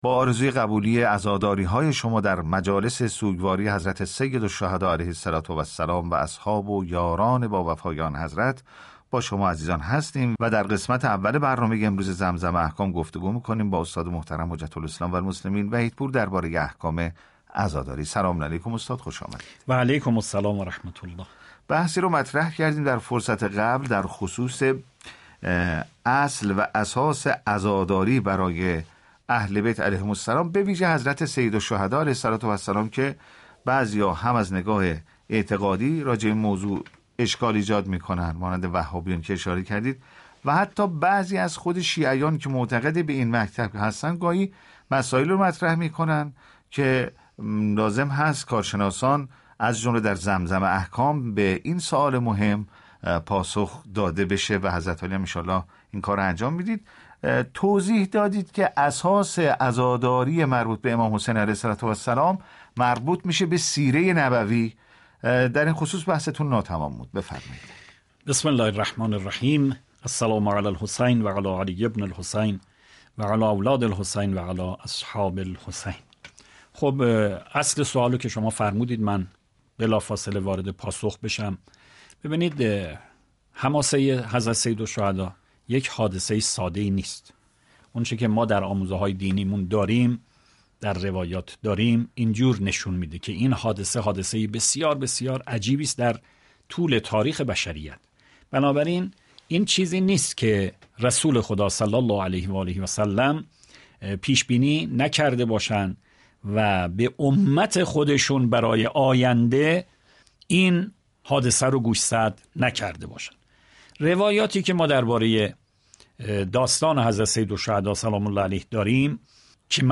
تبیین آداب و احكام شرعی عزاداری در برنامه «زمزم احكام» از آنتن رادیو معارف